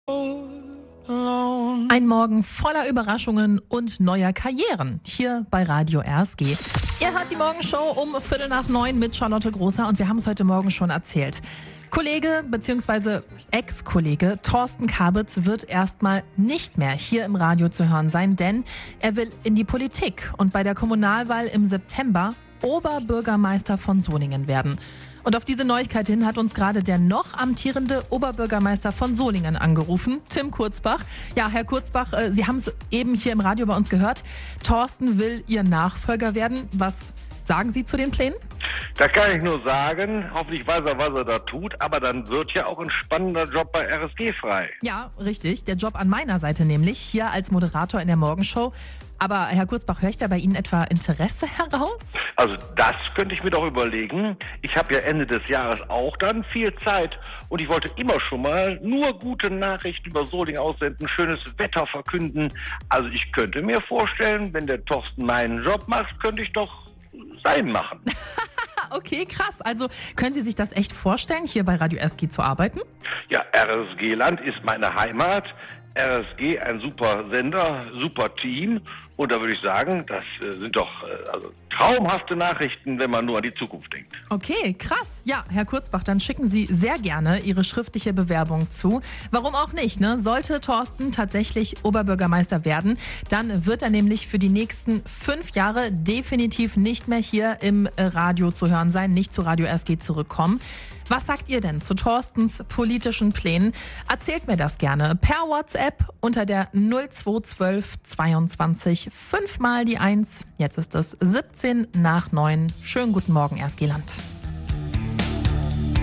Noch während der laufenden Morgenshow meldete sich aber auch der noch amtierende Solinger OB Tim Kurzbach in der Morgenshow.